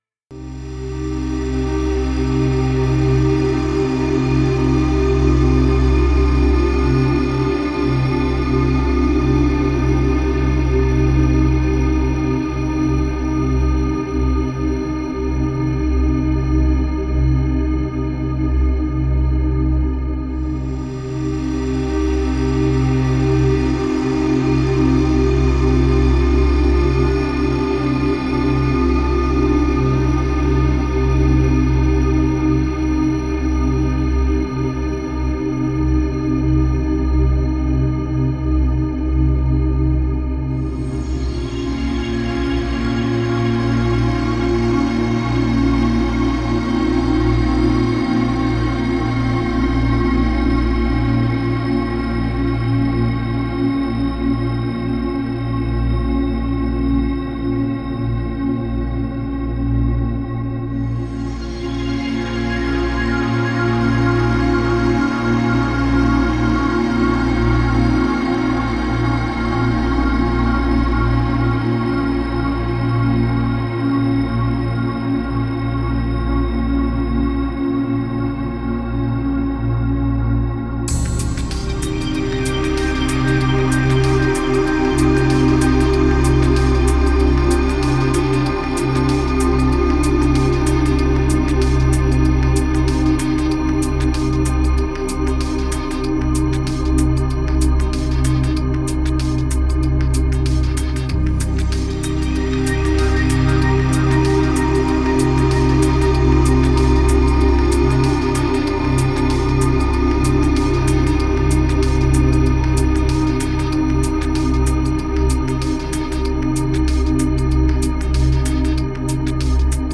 01__Space_Massive_Deep_Atmosphere_Ambient_Mix.mp3